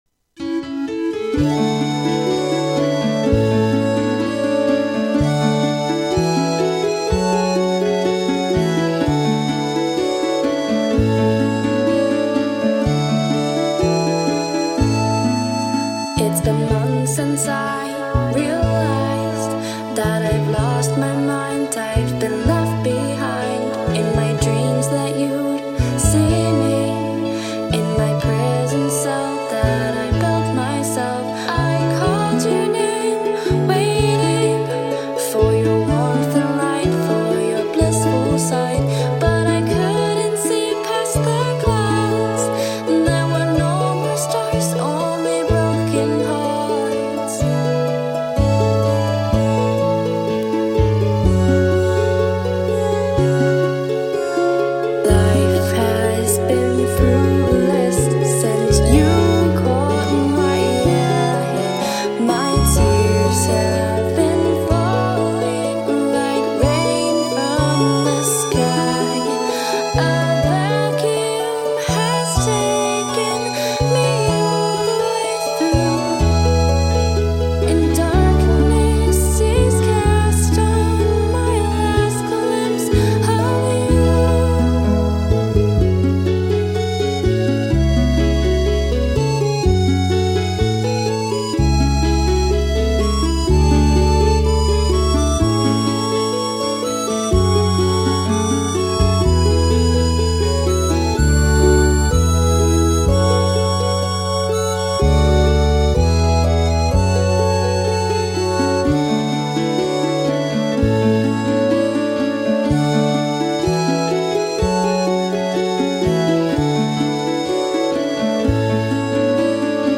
sad midi ballad